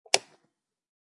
switch_soft.wav